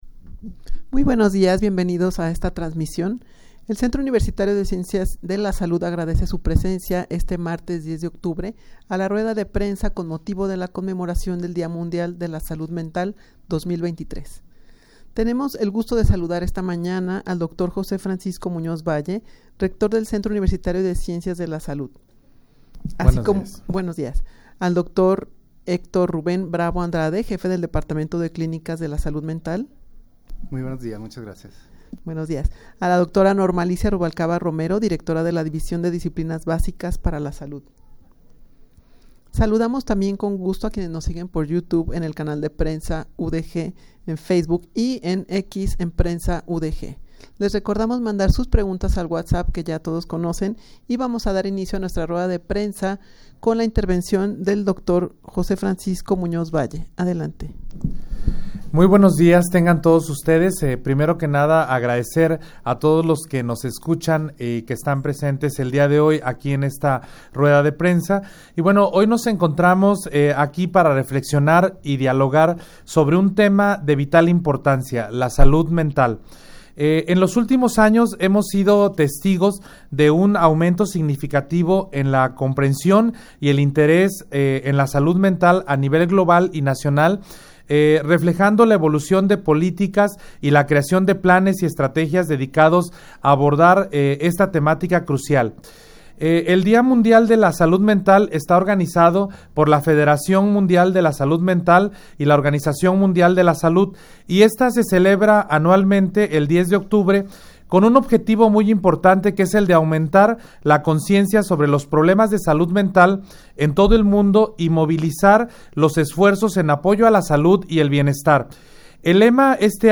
rueda-de-prensa-con-motivo-de-la-conmemoracion-del-dia-mundial-de-la-salud-mental-2023.mp3